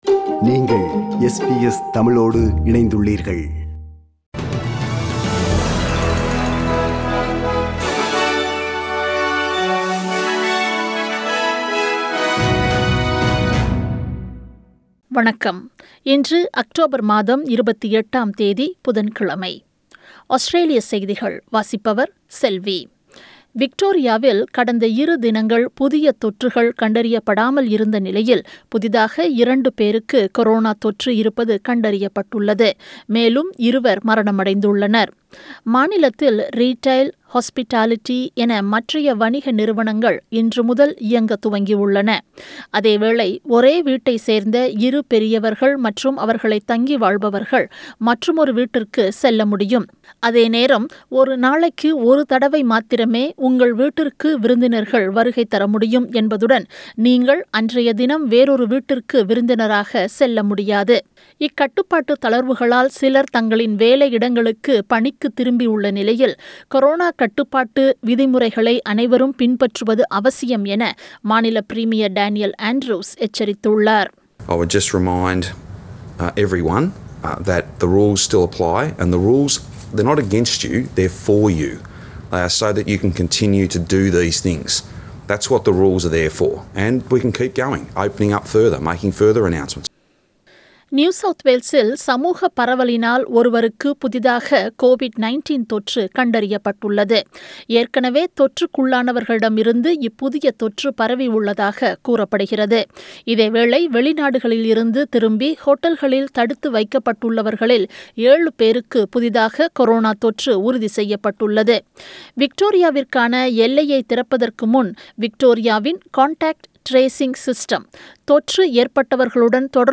Australian news bulletin for Wednesday 28 October 2020.